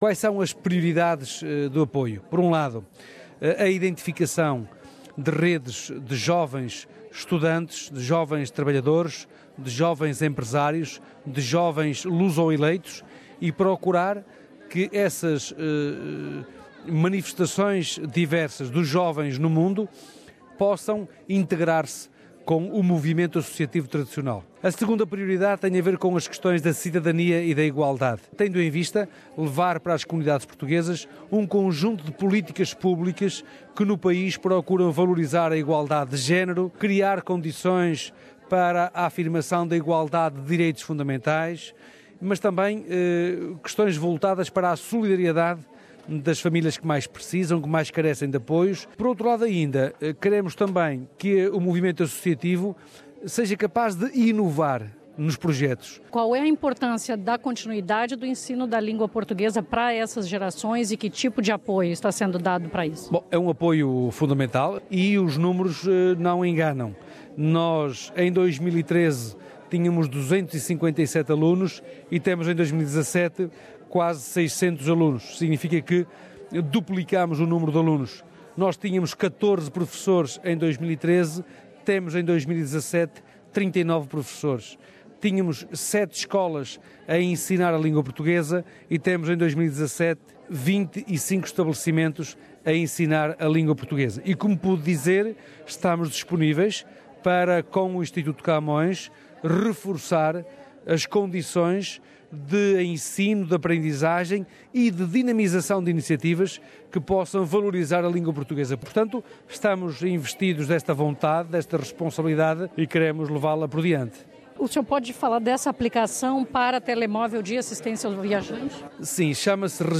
Nesta entrevista à S-B-S, o Secretário falou do seu encontro com a Ministra para o Desenvolvimento Internacional da Austrália, Concetta Fierravanti-Wells.